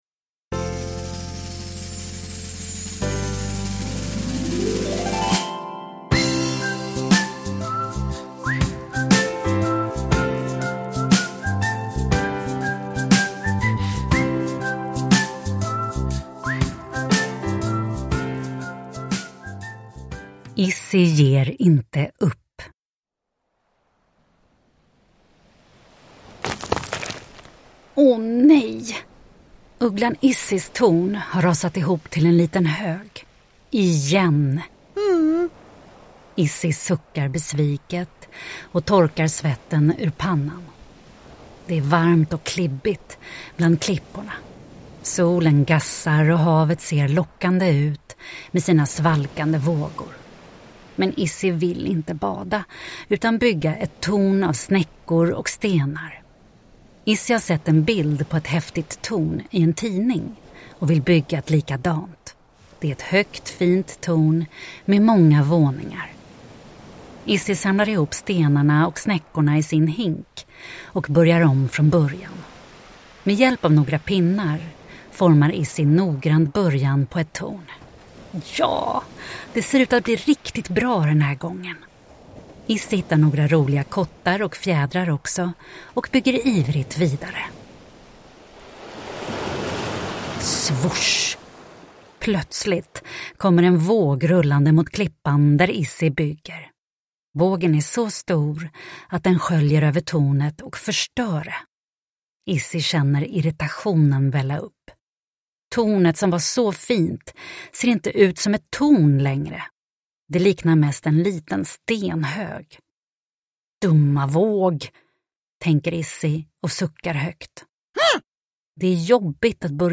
Izzy ger inte upp – Ljudbok – Laddas ner